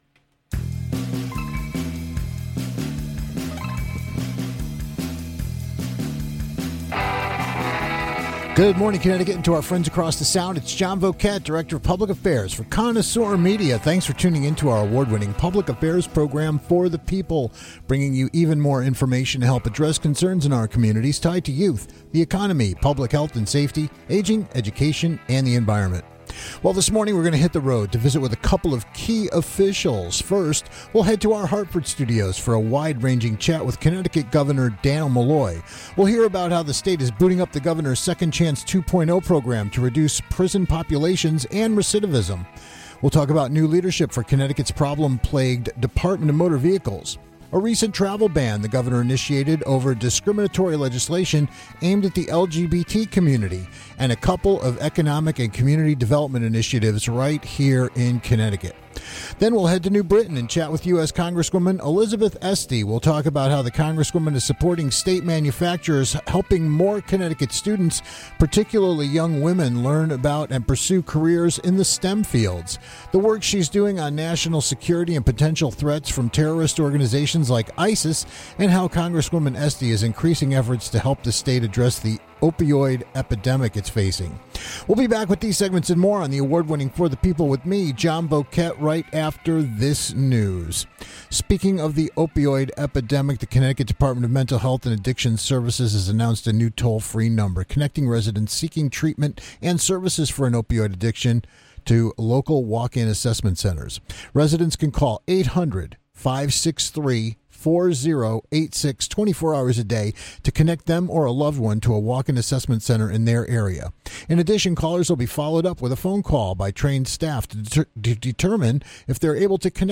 to our Hartford studios to talk about Second Chance 2.0, new DMV leadership and other subjects including how he's using travel bans to protect state LGBT workers. Then we'll visit Congresswoman Elizabeth Esty in New Britain to talk about subjects including our national security and the threat of ISIS, what she's doing about the escalating opioid epidemic and her strong advocacy on STEM education and careers for the states workforce.